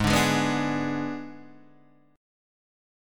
G# Diminished